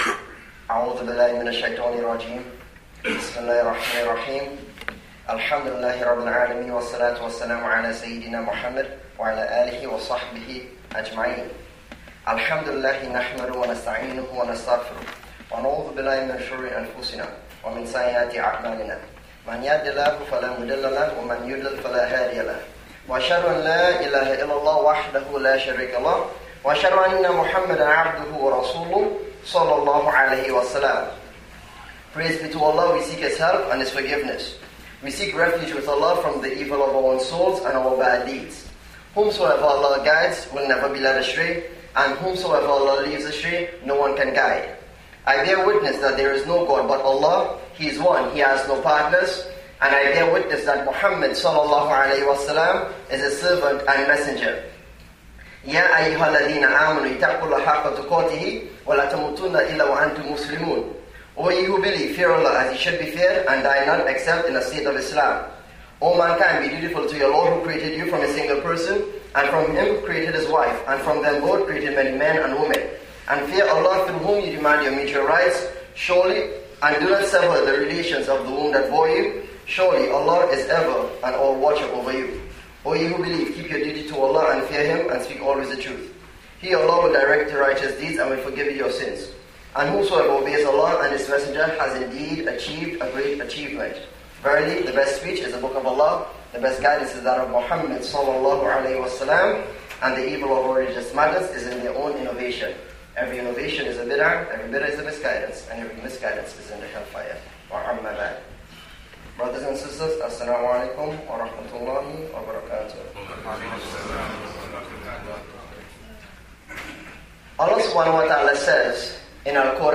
for audio recording of the khutbah)